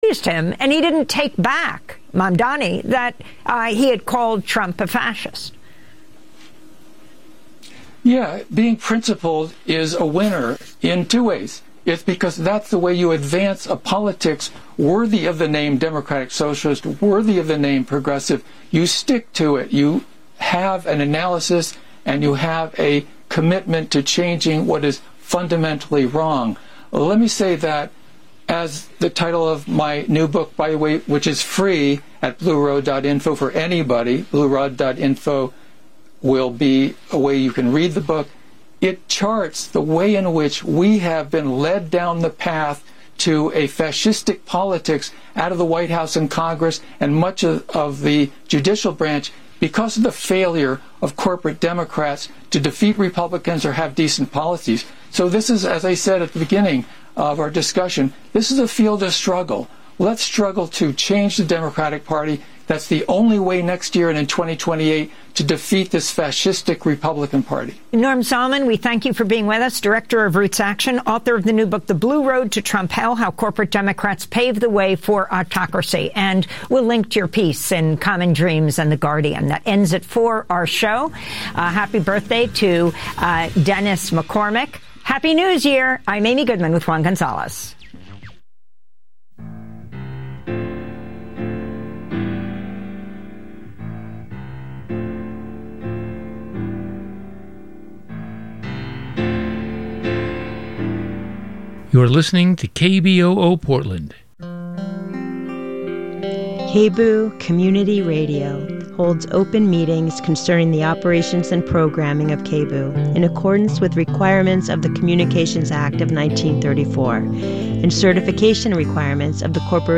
On this Best of KBOO News 2025, we’ll be taking a look back at some of our favorite interviews with the intrepid reporters at Street Roots.